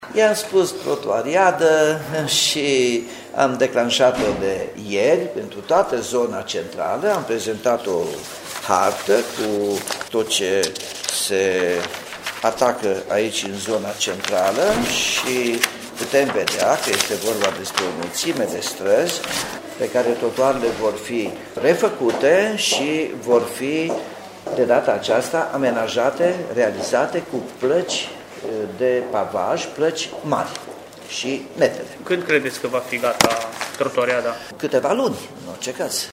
Trotuarele din zona centrală a Timișoarei, pline de noroi după ce au fost sparte cu ocazia lucrărilor de asfaltare a străzilor, vor fi refăcute în următoarele luni. Primarul Nicolae Robu spune că la Timișoara a început „Trotuariada”